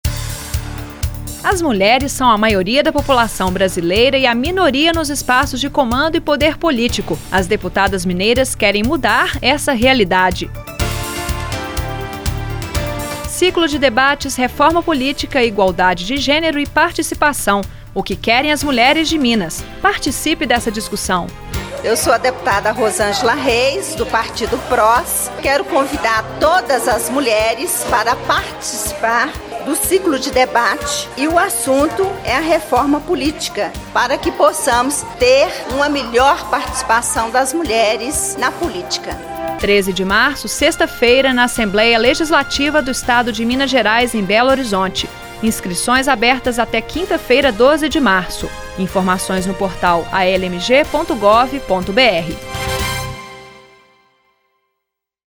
Ouça o convite da deputada Rosângela Reis, PROS